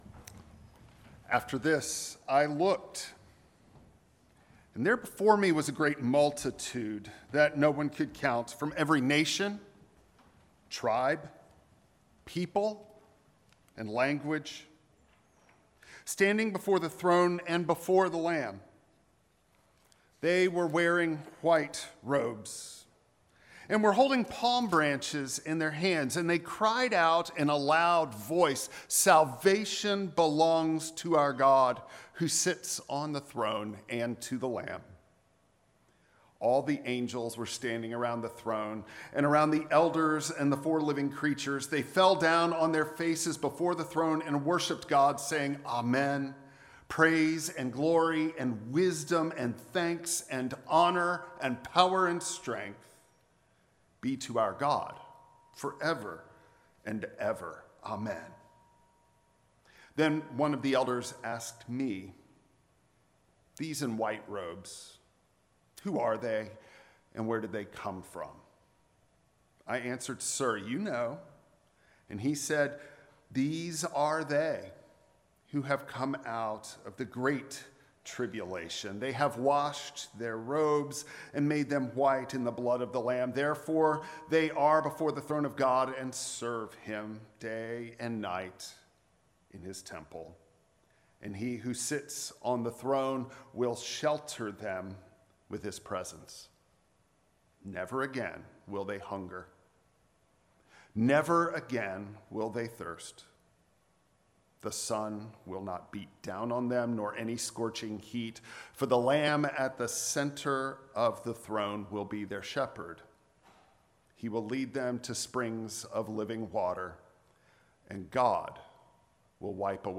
Mark 11:1-11 Service Type: Traditional Service Palm Sunday is a call to look